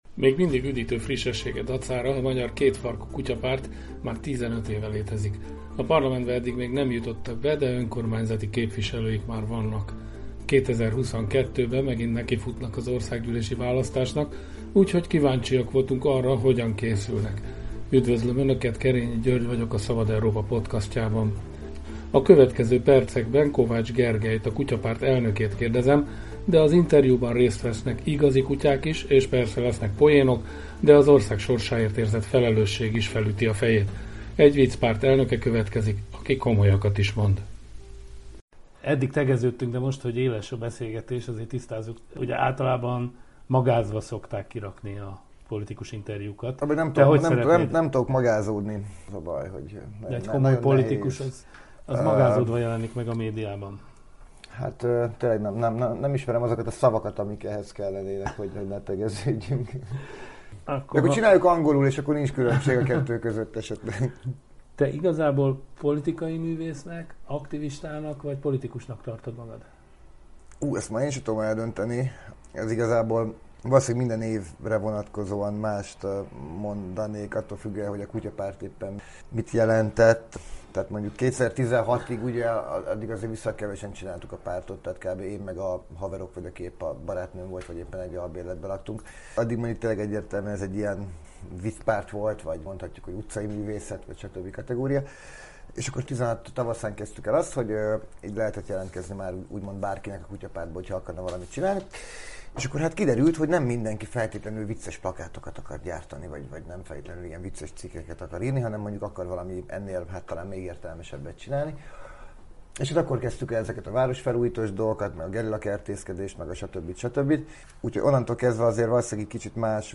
Interjú Kovács Gergellyel, a Magyar Kétfarkú Kutya Párt elnökével, melyben hangot kapnak igazi kutyák is.